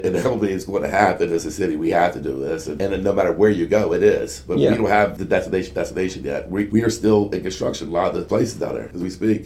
The Cumberland Mayor and City Council motioned to remove the order regarding the parking changes on Baltimore Street during last night’s Public Meeting.
Councilman James Furstenberg says Baltimore Street is still too much of a construction zone to implement parking changes…